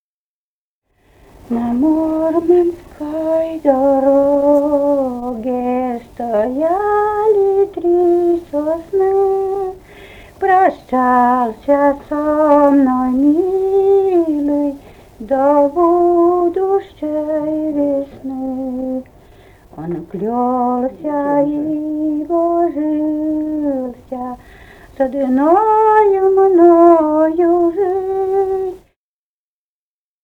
«На Муромской дороге» (лирическая).